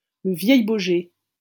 -Le Vieil-Baugé.wav 来自 Lingua Libre 项目的发音音频文件。 语言 InfoField 法语 拼写 InfoField Le Vieil-Baugé 日期 2020年11月14日 来源 自己的作品
pronunciation file